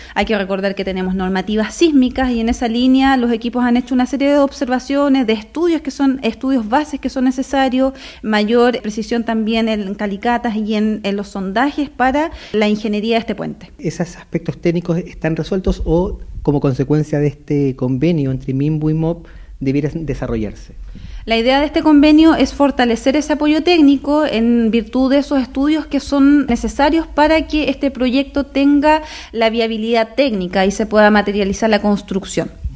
La seremi de Obras Públicas, Nuvia Peralta, indicó que como organismo que apoya al Minvu, consideran que es importante sacar adelante la iniciativa.